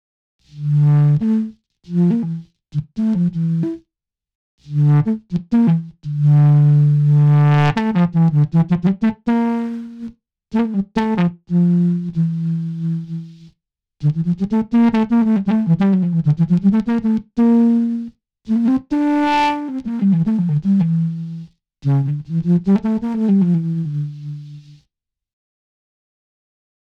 Especially with wind synths, it sounds so much more natural.
Here’s Respiro with the drum buss, glue compressor, reverb and a little bit of erosion :